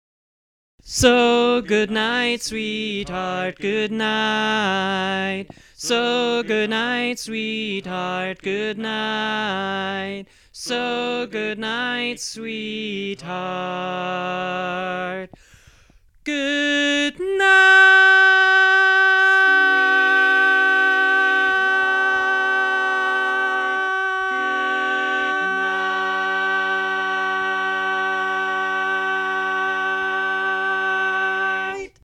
Key written in: G Major
Type: Barbershop
Each recording below is single part only.